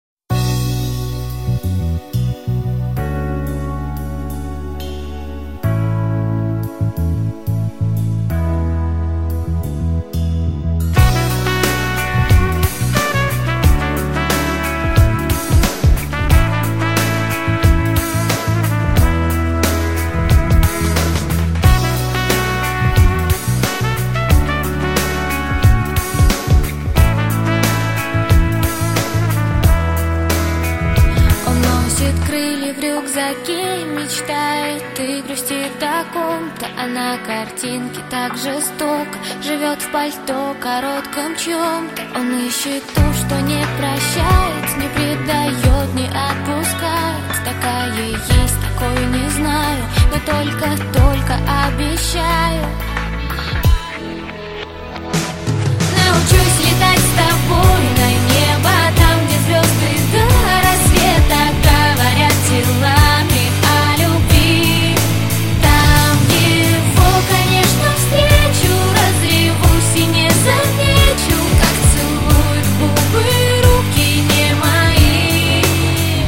Pop
бас гитара
барабаны
композиции в стиле босса нова, и песни с  настроением рока.